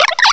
cry_not_oshawott.aif